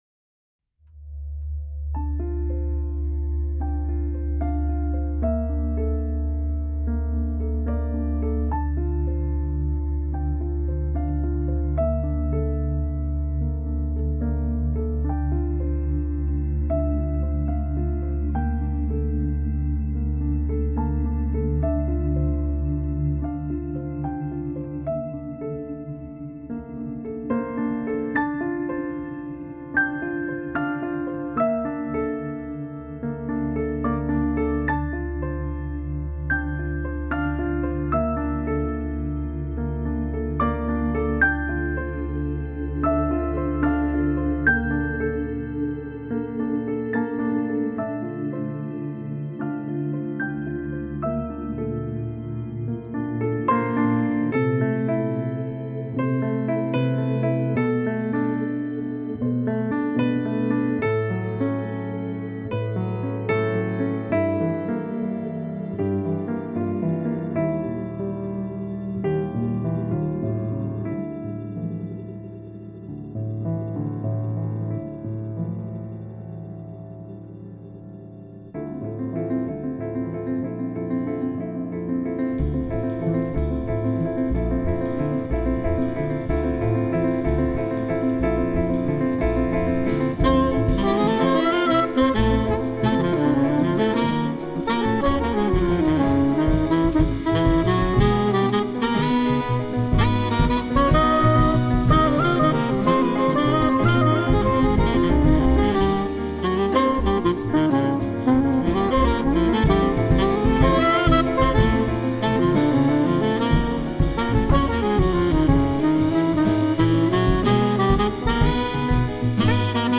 all saxes
piano, electric piano
double bass, banjo
drums